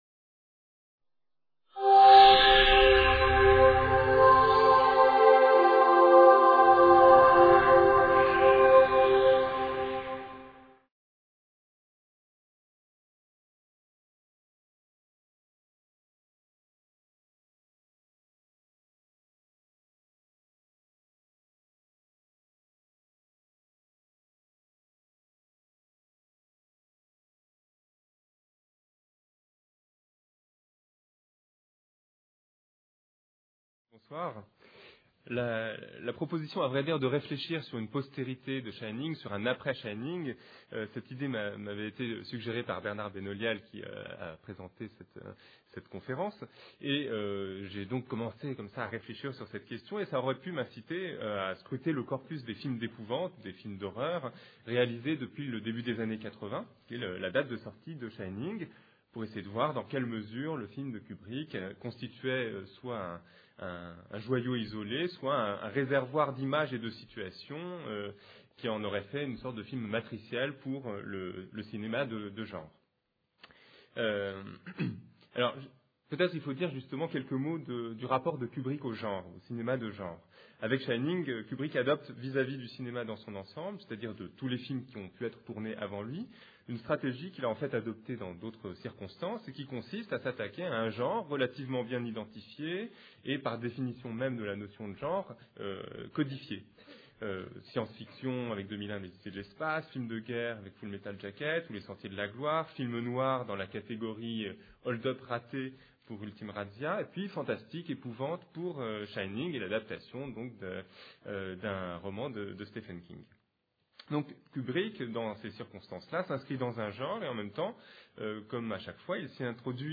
Le cerveau et le monde : Shining et après. Conférence